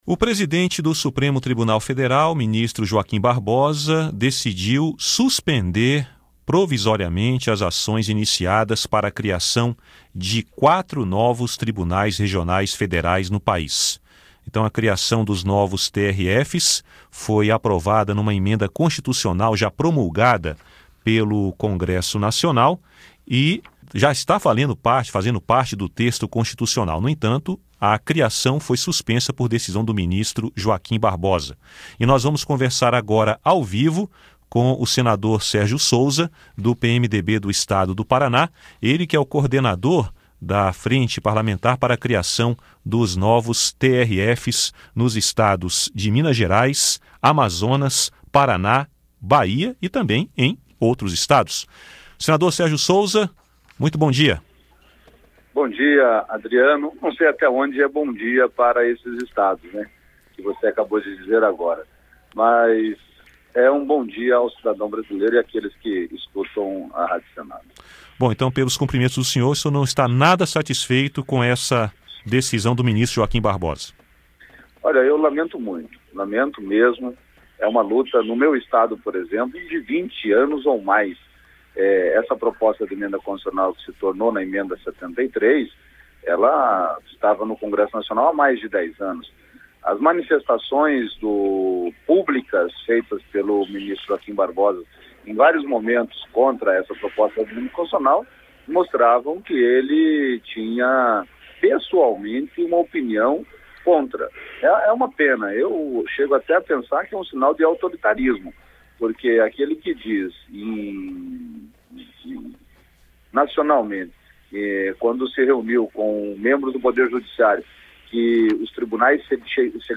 Entrevista com o senador Sérgio Souza (PMDB-PR).